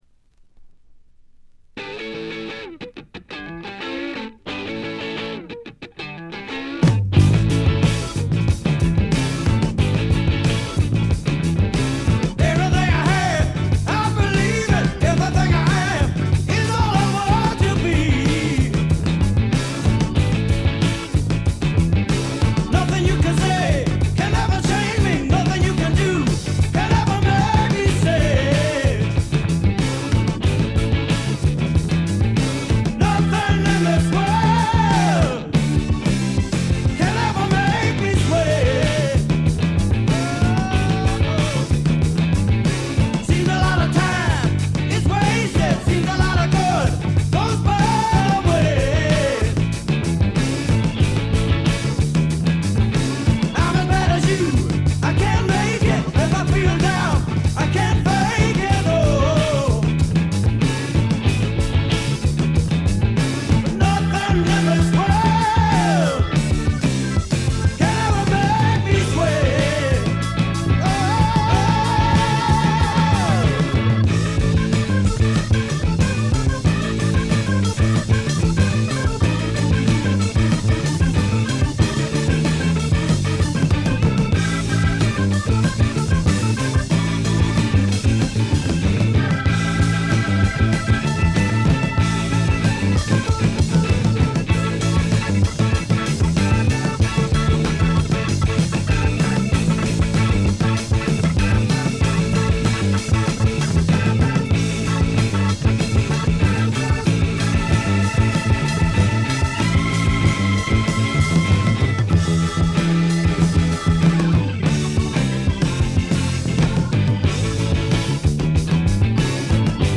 ところどころでチリプチ。散発的なプツ音2-3回。
試聴曲は現品からの取り込み音源です。